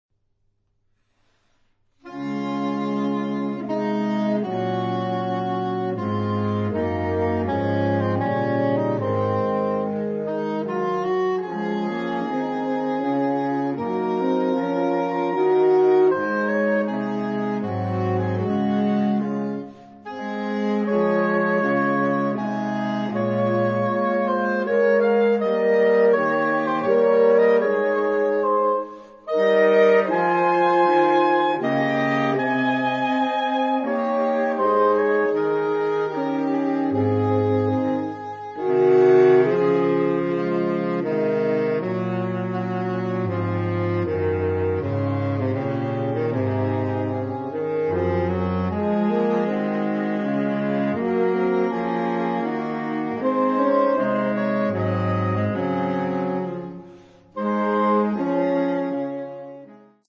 Gattung: für Blechbläserquartett
Besetzung: Ensemblemusik für 4 Blechbläser